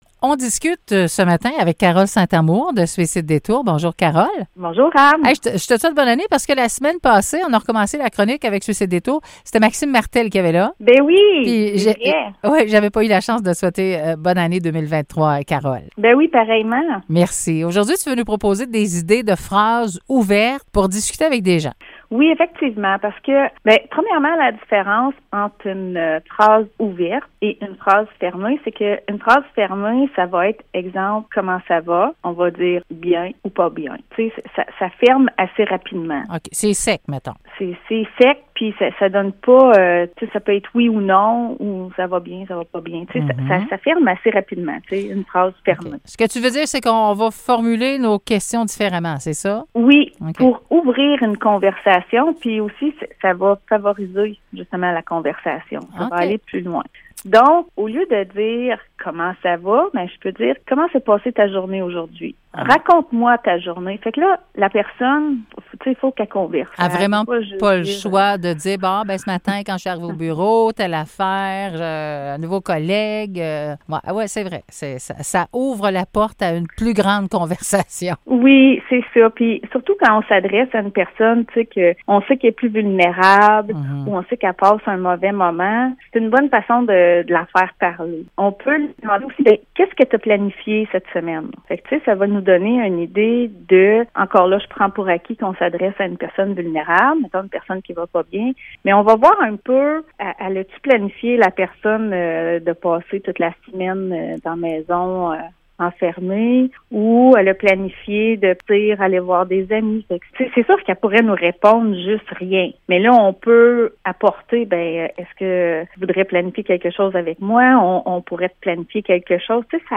Chronique de Suicide détour